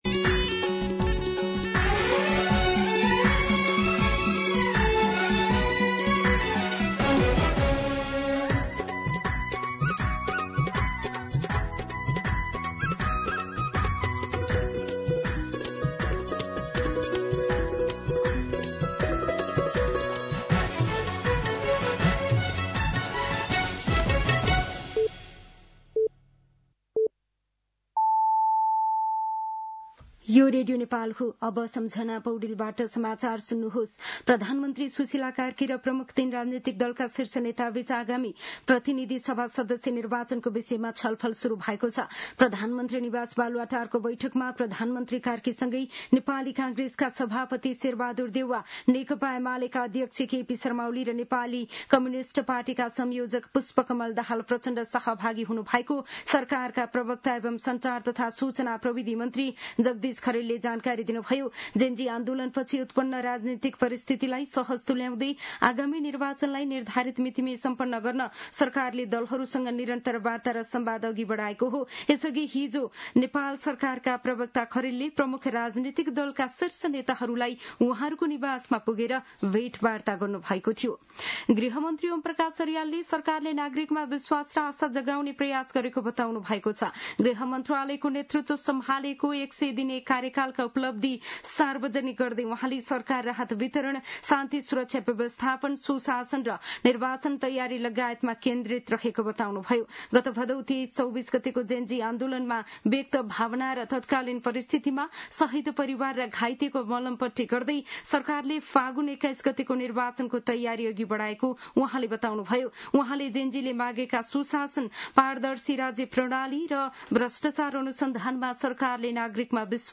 दिउँसो ४ बजेको नेपाली समाचार : १२ पुष , २०८२
4pm-Nepali-News.mp3